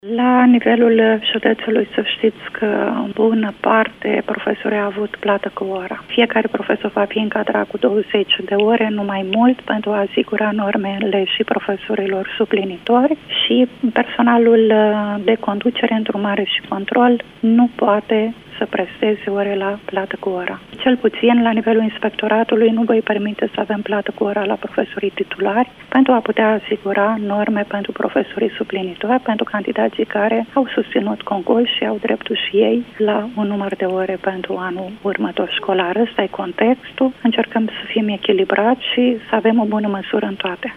Într-un interviu pentru postul nostru de radio, șefa Inspectoratului Școlar Județean, Rodica Leontieș, a declarat  că își dorește să elimine plata cu ora pentru titulari, astfel încât să poată fi asigurate și normele profesorilor suplinitori.